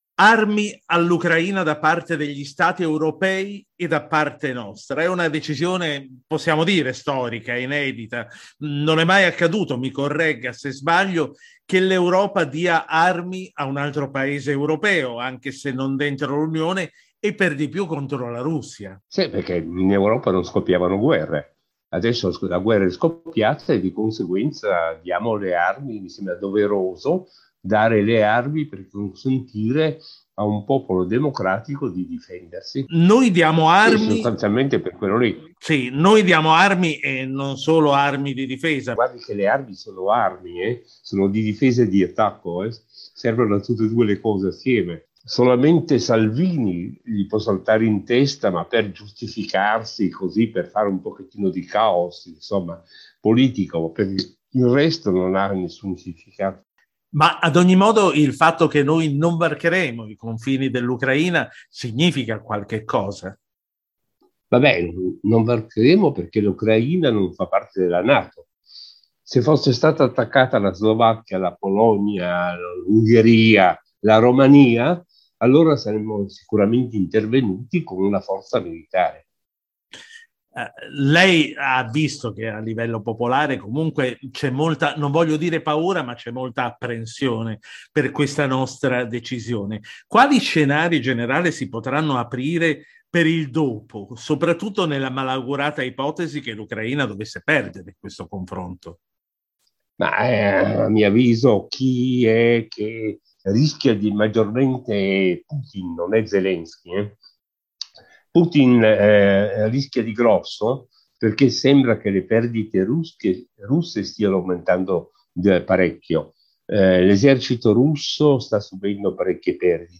Intervista-gen-Jean-per-Start-2-marzo-22.mp3